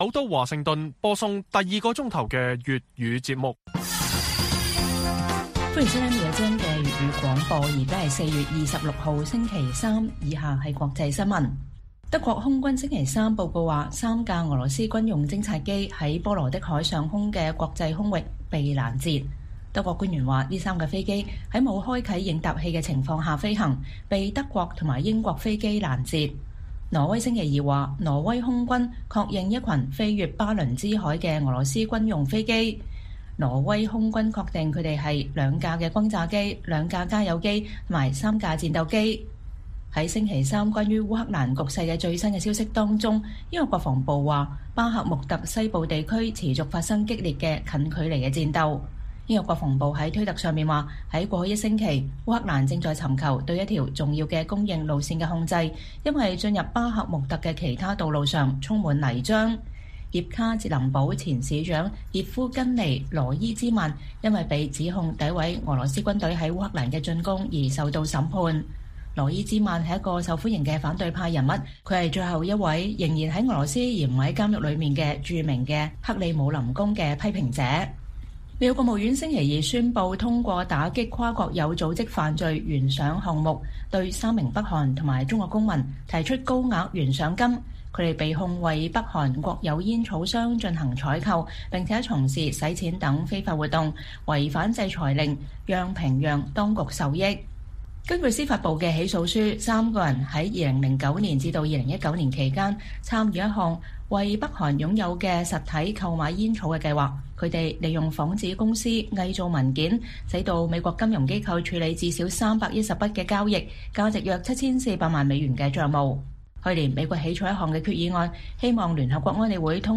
粵語新聞 晚上10-11點 : 世界媒體看中國：駐法大使的驚世狂言及後果